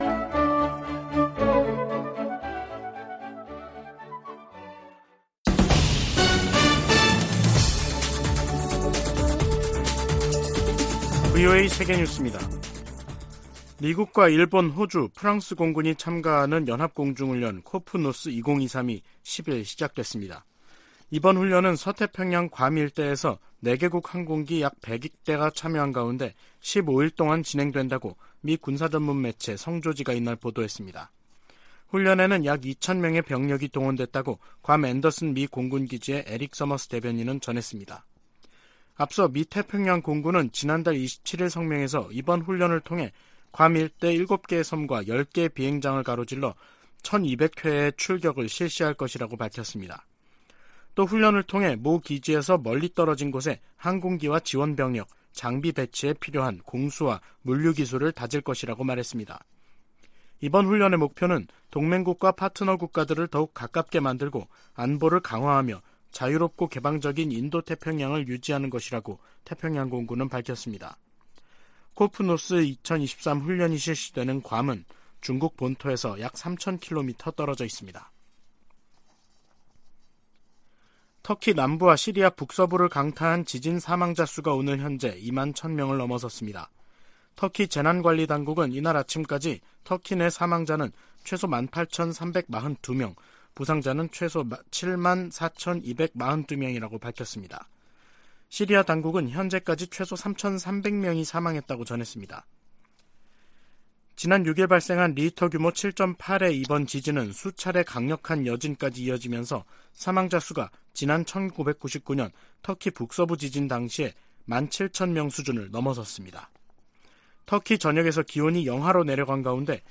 VOA 한국어 간판 뉴스 프로그램 '뉴스 투데이', 2023년 2월 10일 3부 방송입니다. 한국 정부가 사이버 분야에 첫 대북 독자 제재를 단행했습니다. 미국 국무부는 북한이 건군절 열병식을 개최하며 다양한 무기를 공개한 상황에서도 한반도 비핵화 목표에 변함이 없다고 밝혔습니다.